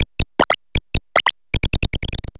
Cri de Famignol Famille de Quatre dans Pokémon HOME.